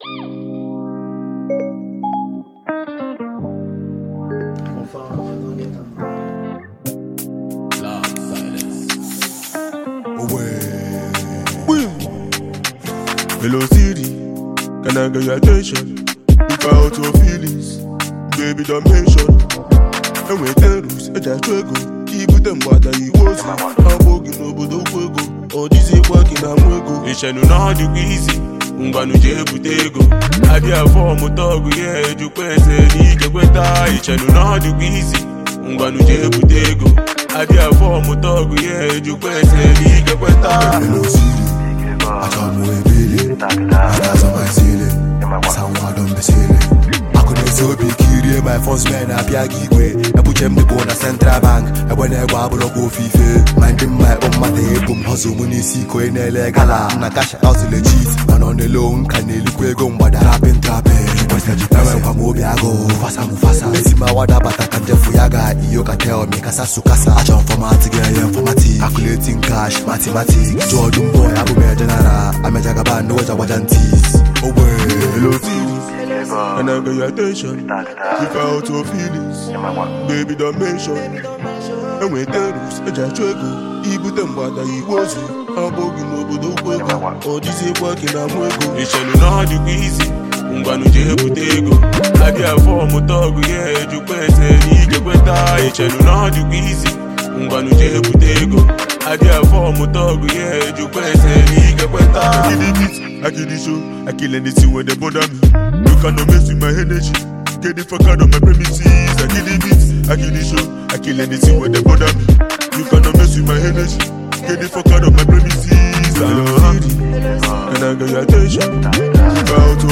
Talented Nigerian rap sensation, singer, & songwriter